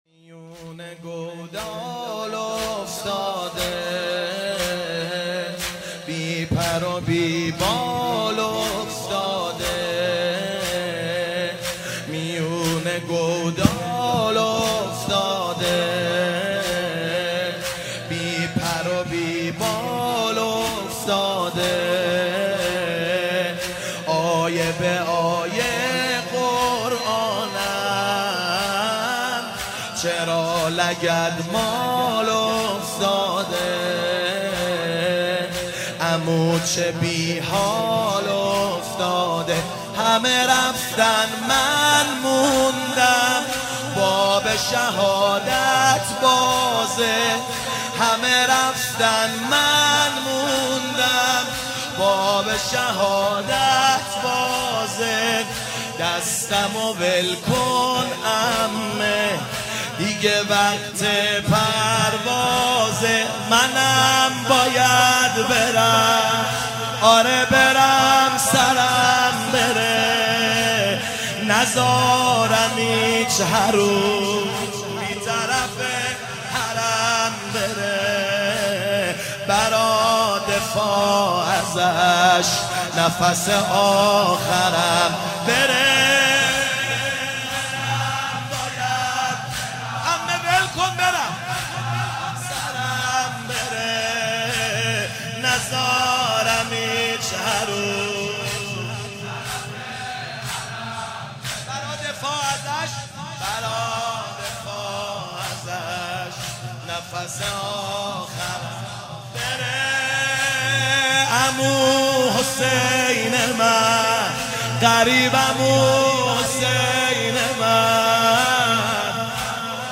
شب پنجم محرم 97 - زمینه - میون گودال افتاده